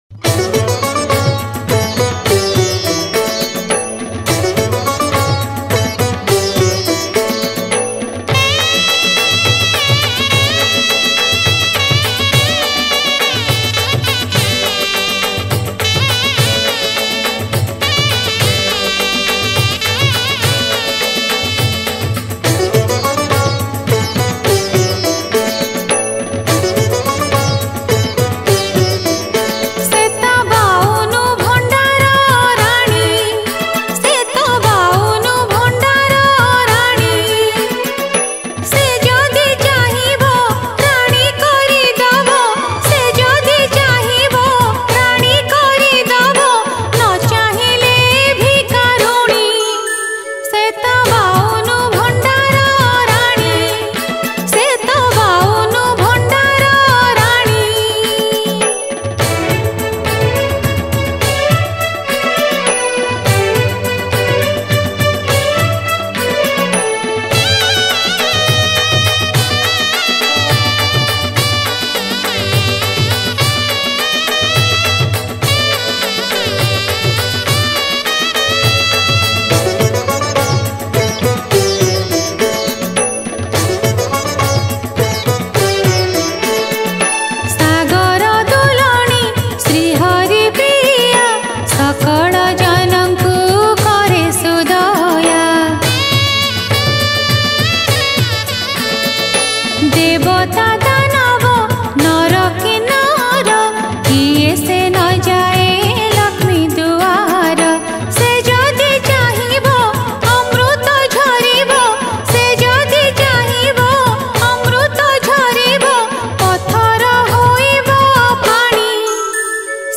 Manabasa Gurubara Bhajan Songs Download